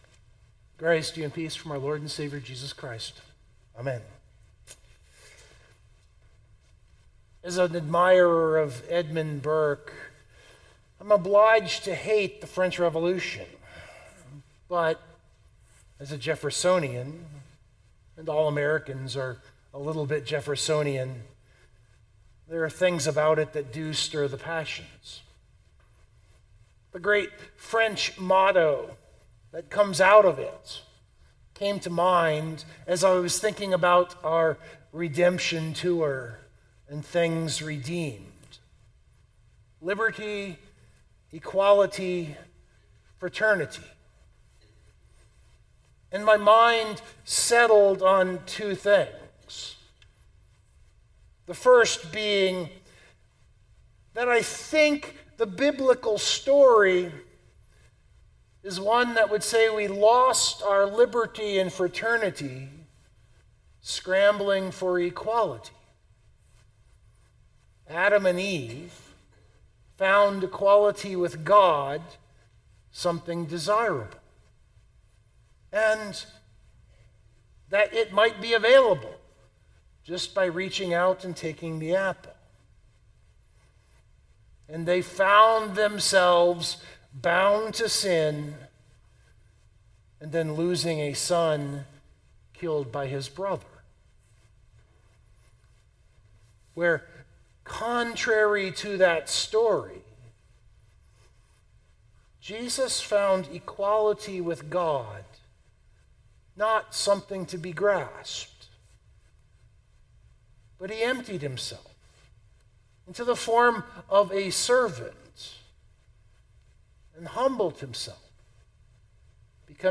That is the opening to this sermon. And the overriding theme is that in our sinful quest for equality with God, we lost liberty and fraternity.